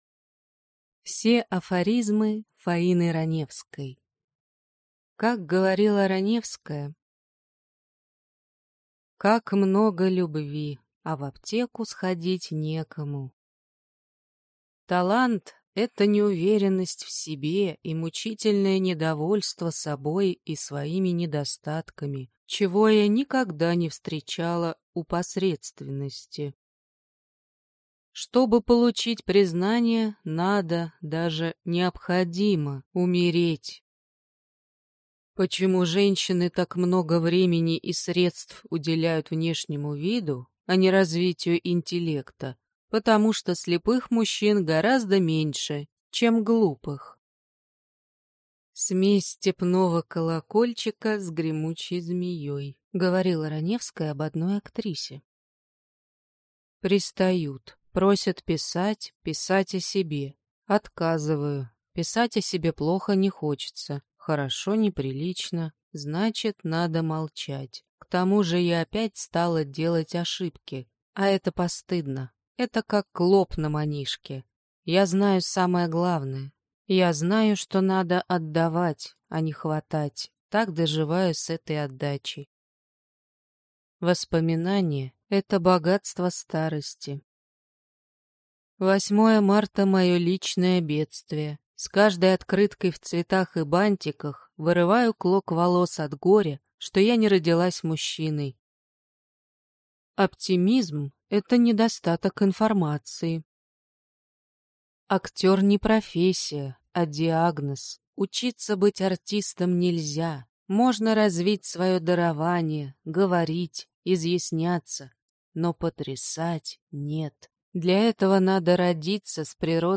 Аудиокнига Все афоризмы Фаины Раневской | Библиотека аудиокниг